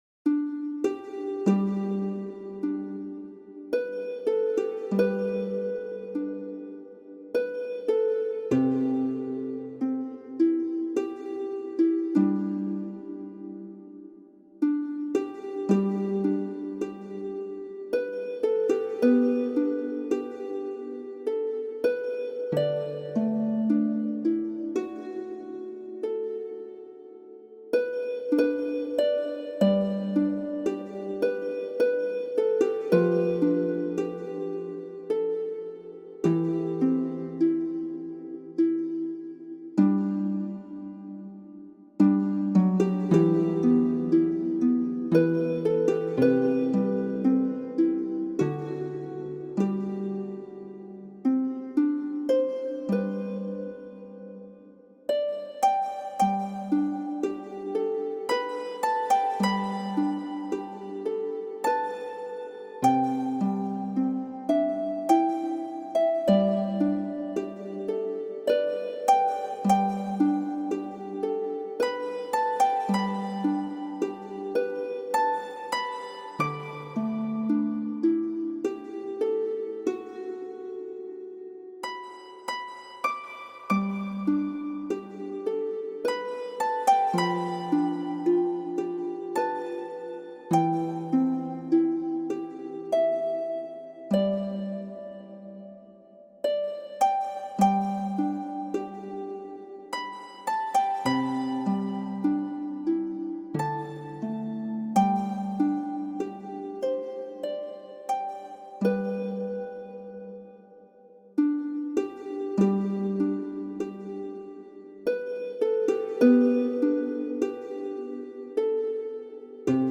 The Amazing Grace Harp Technique That Heals Trauma While You Sleep